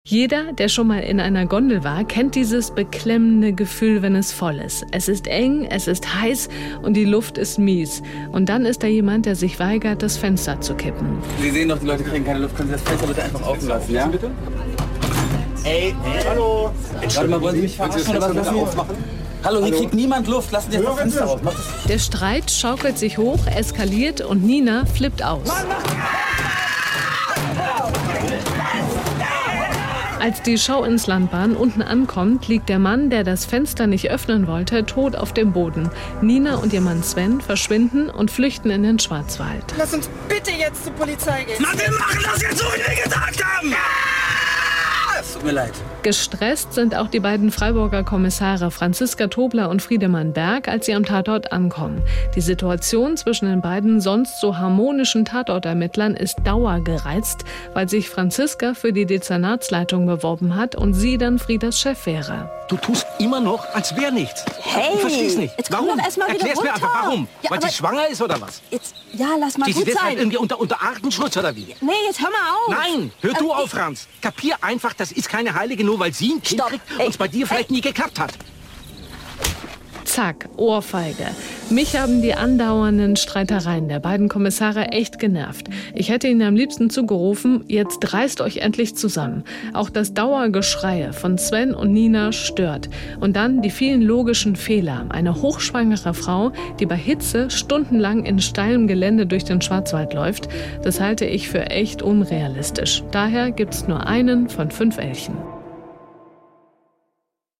3. Tatort-Kritik